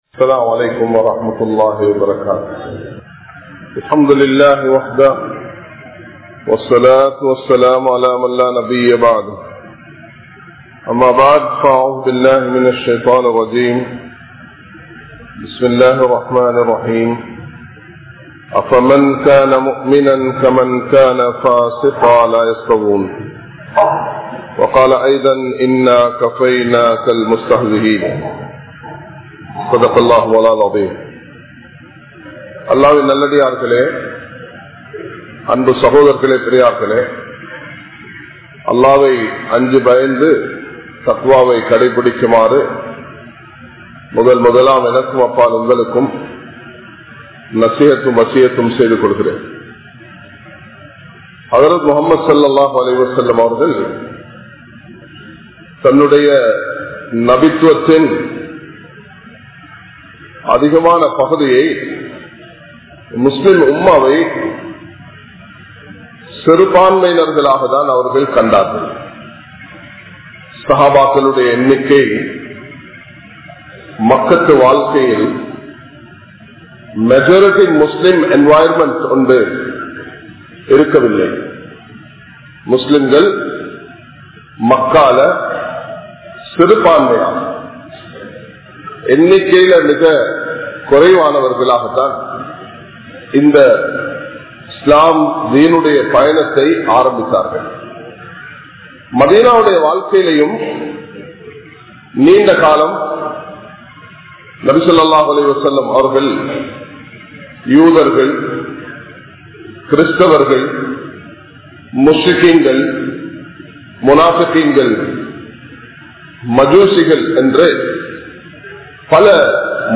How To Face Today’s Challenges | Audio Bayans | All Ceylon Muslim Youth Community | Addalaichenai
Dehiwela, Muhideen (Markaz) Jumua Masjith